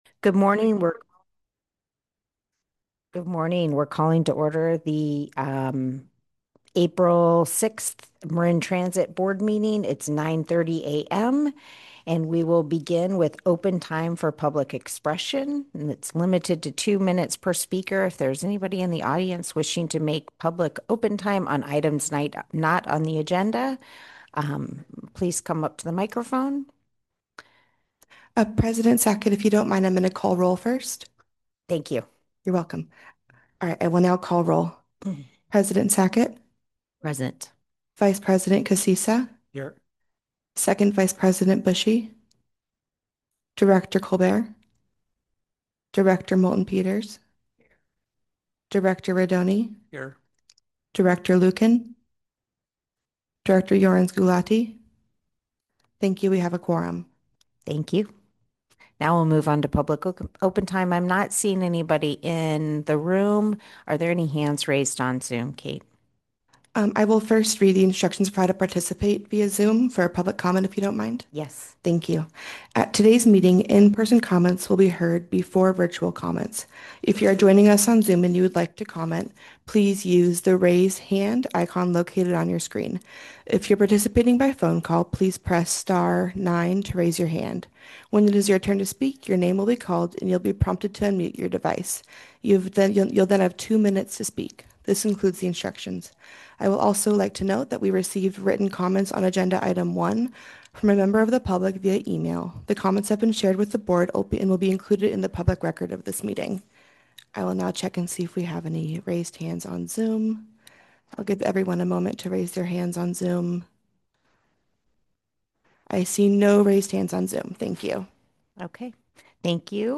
April 2026 Board of Directors Meeting
Marin Transit's January, February, March, and April 2026 Board meetings will be held in Suite 335 at 1600 Los Gamos Drive.